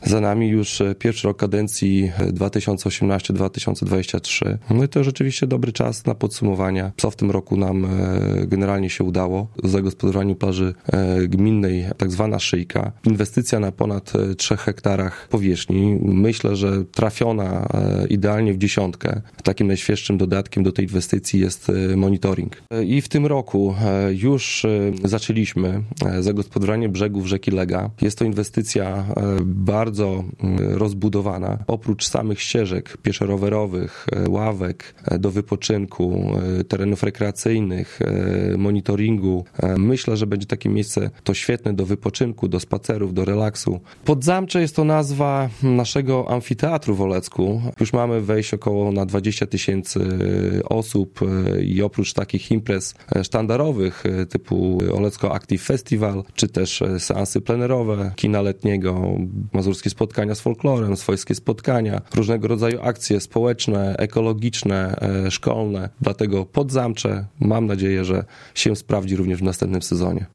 Mijający 2019 rok podsumował w piątek (27.12) na antenie Radia 5 Karol Sobczak, burmistrz Olecka.